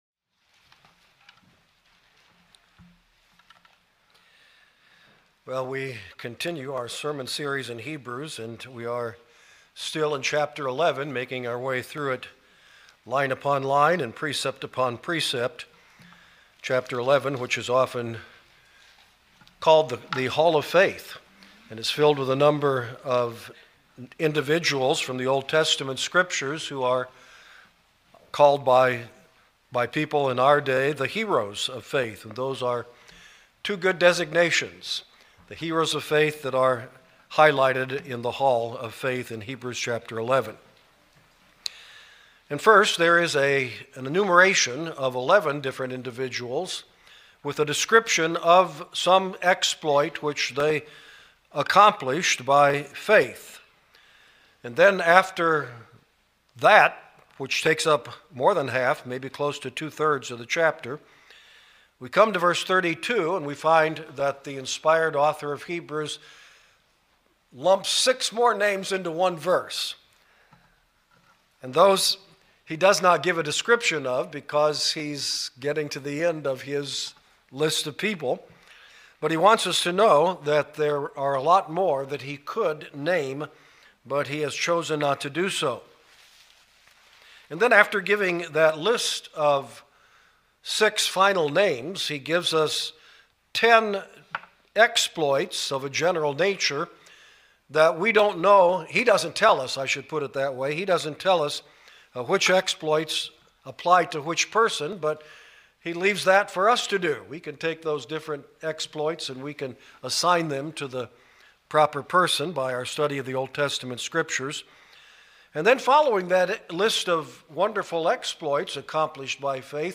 In this message from Hebrews 11, we learn of amazing exploits accomplished through faith that encourage God's people to exercise their own faith.